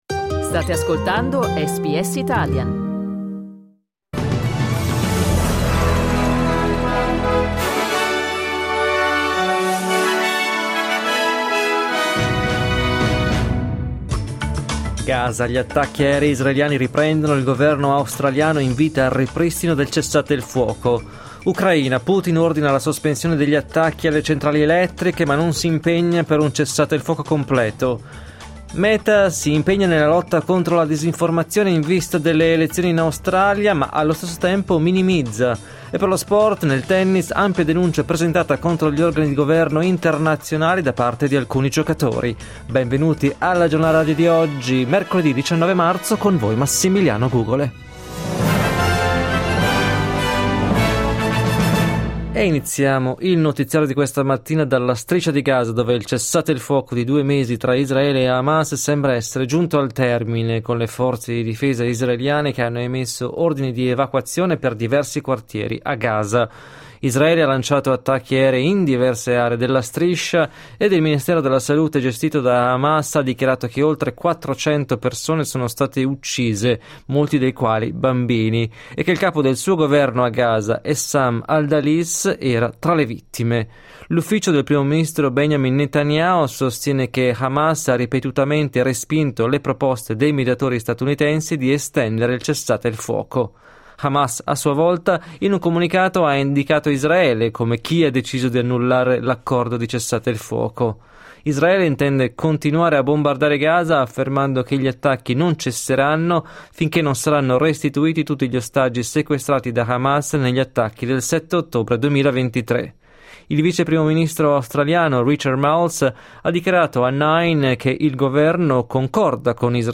Giornale radio mercoledì 19 marzo 2025
Il notiziario di SBS in italiano.